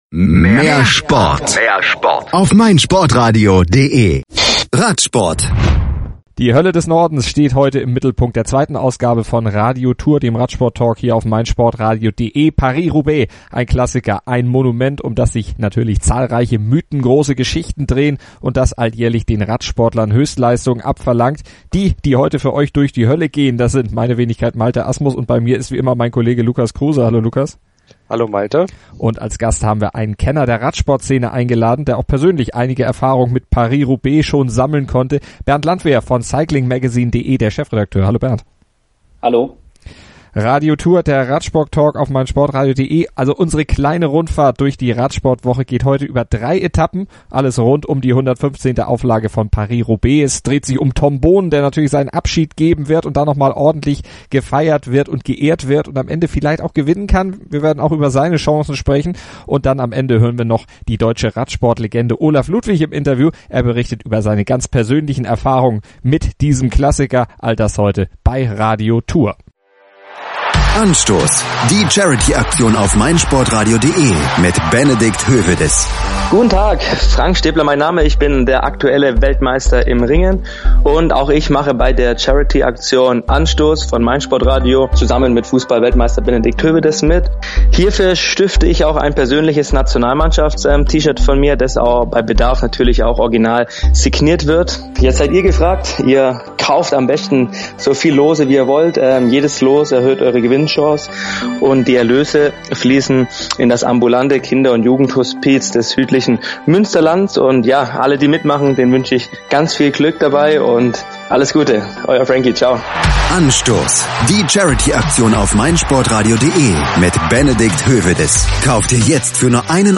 Und sie schauen auf die 2017er-Edition voraus, auf die Strecke und die Favoriten. Ein Schwerpunkt ist der Abschied von Tom Boonen, der nach Paris-Roubaix 2017 seine lange Karriere beenden wird. Und zum Abschluss berichtet Olaf Ludwig, die deutsche Radsportlegende, über seine ganz persönlichen Erfahrungen mit dem Rennen.